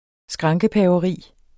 Udtale [ -pæːvʌˌʁiˀ ]